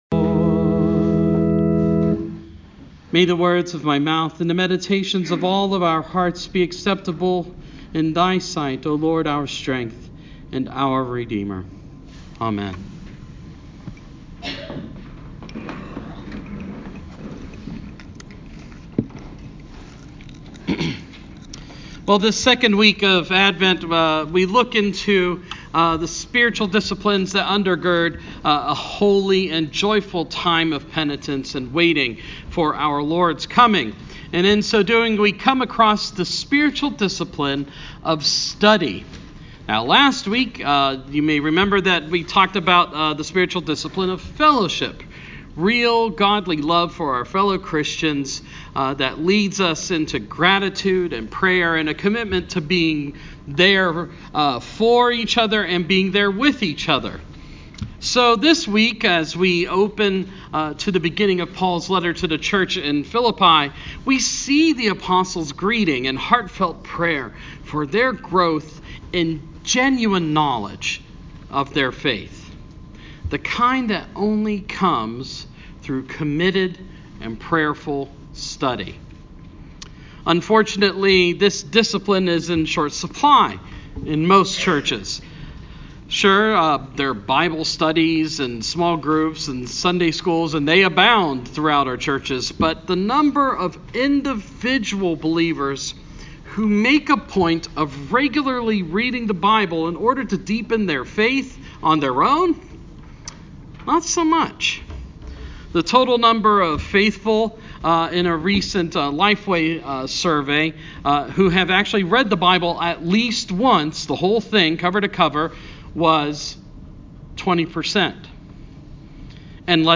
Sermon – Advent 2 – 2018